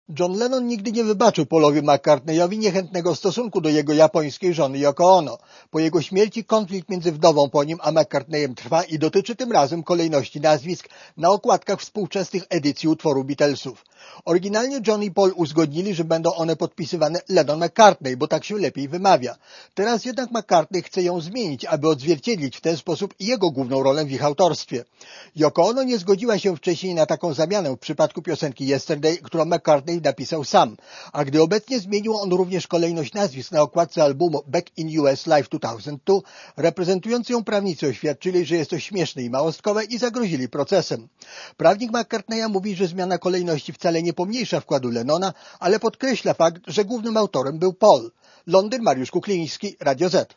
Niewykluczone, że obecny konflikt skończy się w sądzie. 18 grudnia 2002, 22:12 ZAPISZ UDOSTĘPNIJ SKOMENTUJ © (RadioZet) Posłuchaj relacji